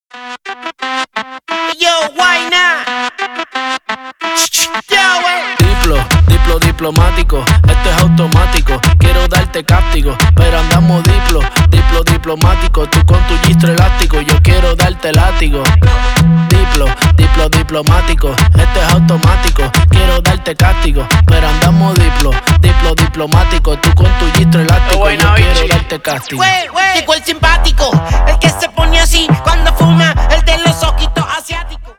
латинские
рэп